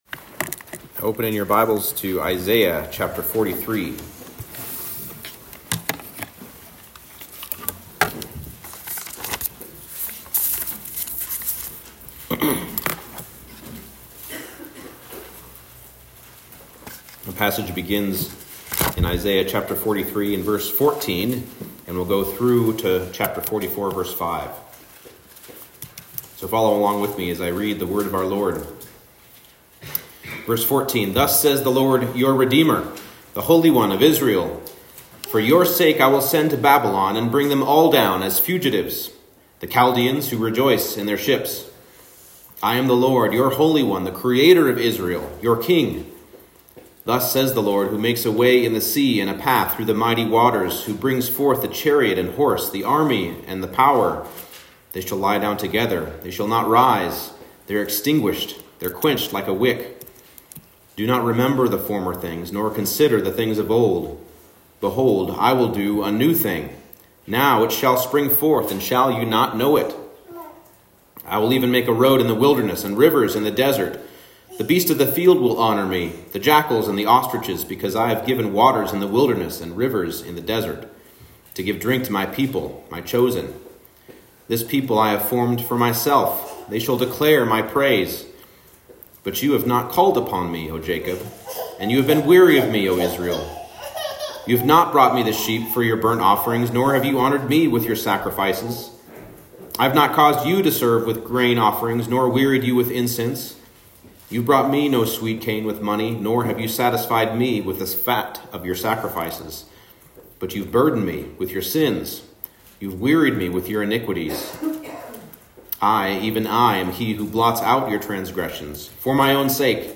Isaiah 43:14-44:5 Service Type: Morning Service The redemption of Israel was an un-asked-for gift